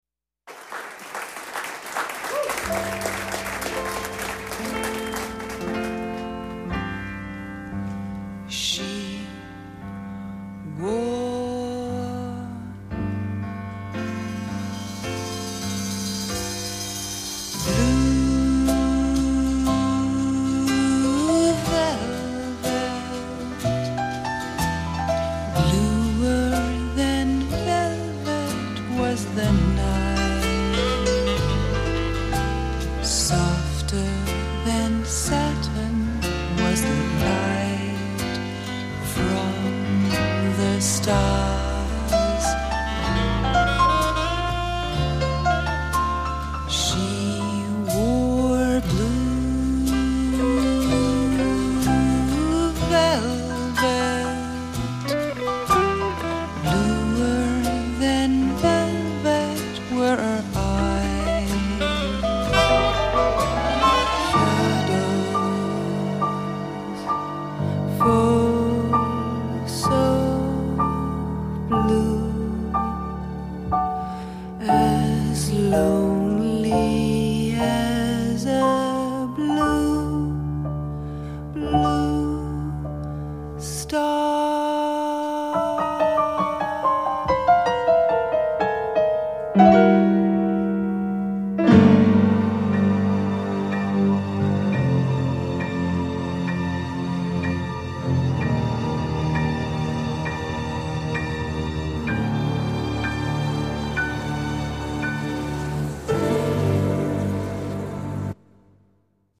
baladas clásicas